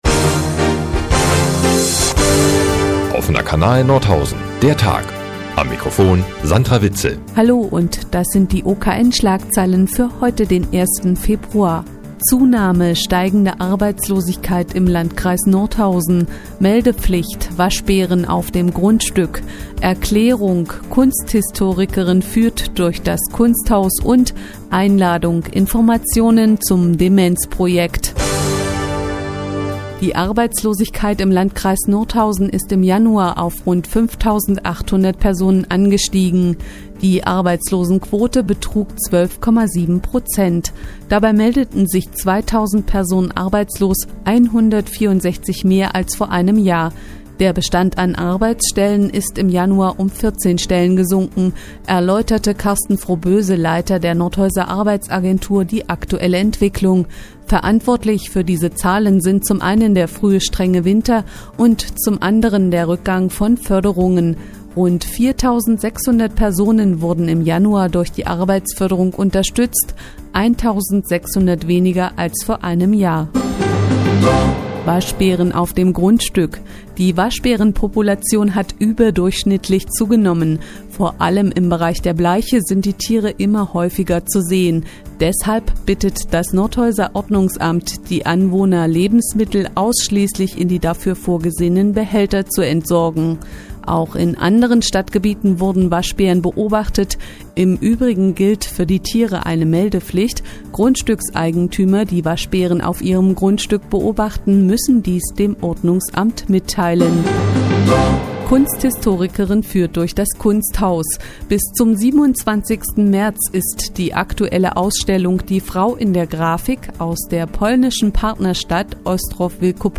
Die tägliche Nachrichtensendung des OKN ist hier zu hören.